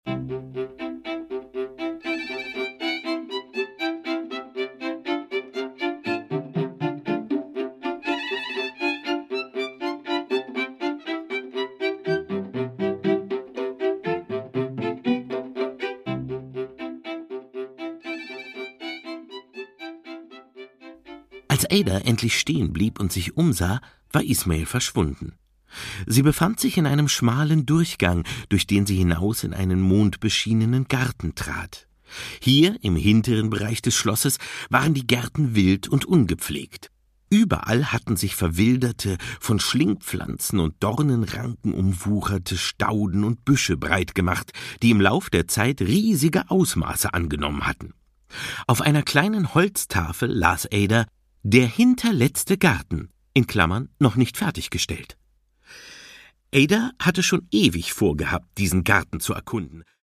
Produkttyp: Hörbuch-Download
Gelesen von: Oliver Kalkofe